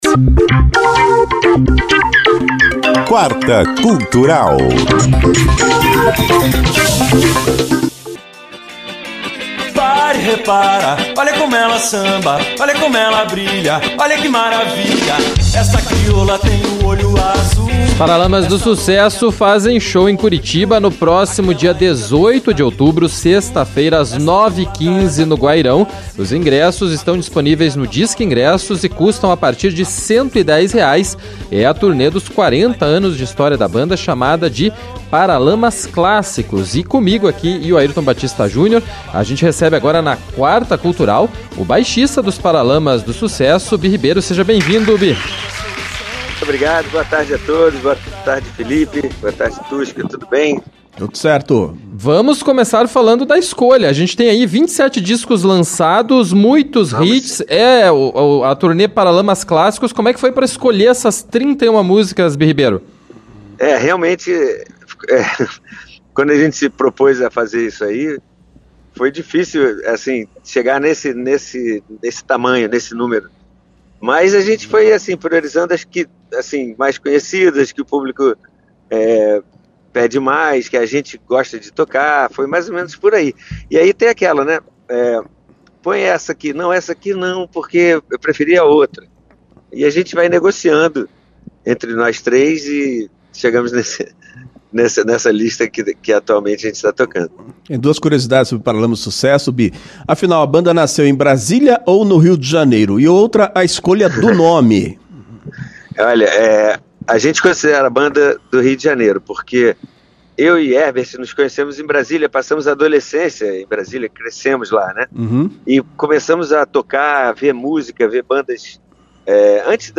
É a turnê dos quarenta anos de história da banda chamada de “Paralamas Clássicos”. O baixista da banda, Bi Ribeiro, participou da Quarta Cultural, dentro do CBN Curitiba 2ª edição desta quarta-feira (9), e falou mais sobre o show.